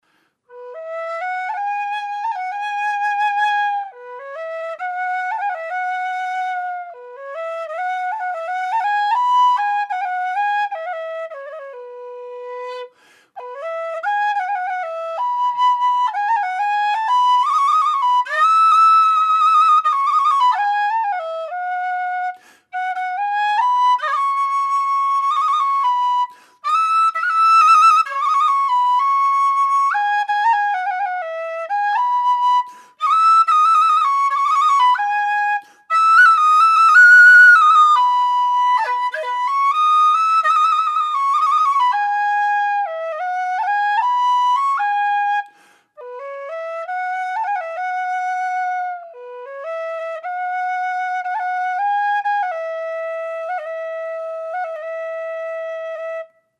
Listen to B sample 1 (:audioplayer Audio:B-impro-1.mp3:) (improvisation) Listen to B sample 2 (:audioplayer Audio:B-impro-2.mp3:) (improvisation)
B whistle
made out of thin-walled aluminium tubing with 14mm bore